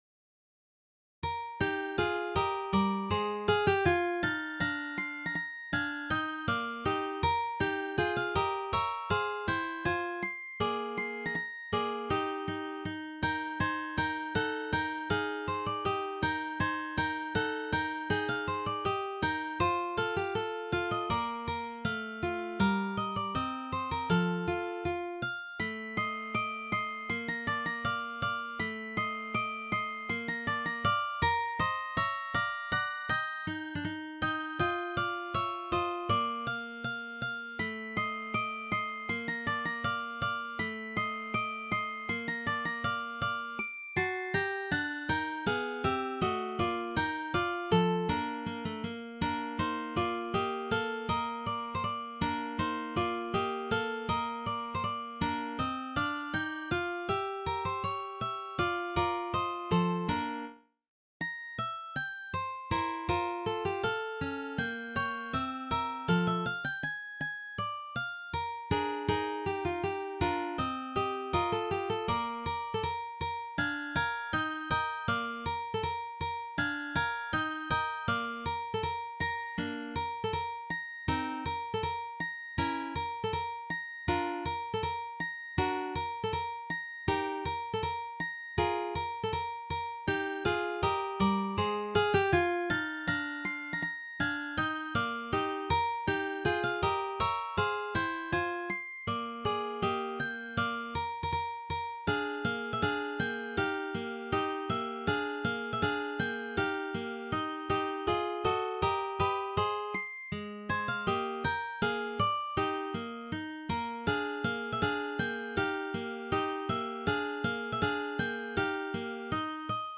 arrangement for 3 guitars